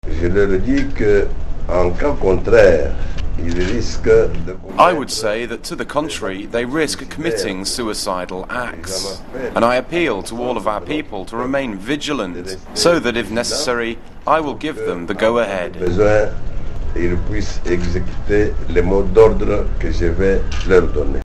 drc_son_tshisekedi_dubbed.mp3